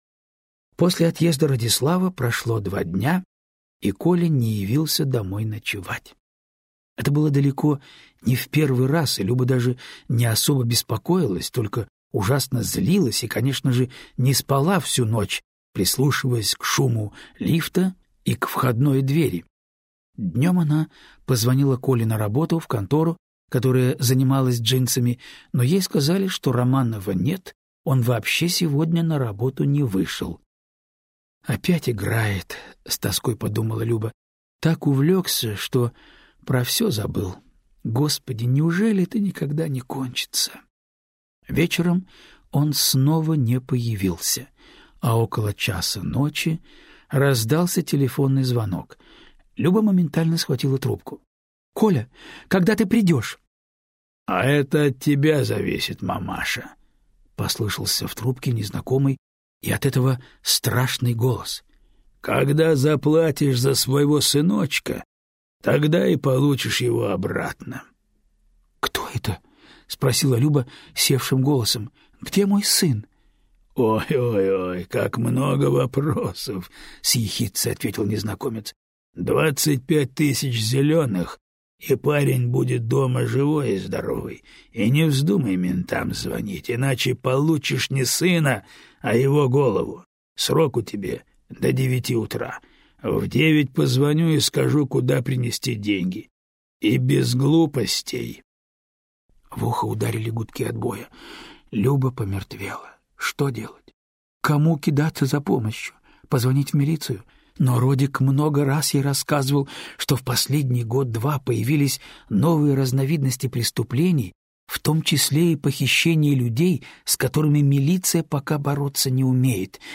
Аудиокнига Ад | Библиотека аудиокниг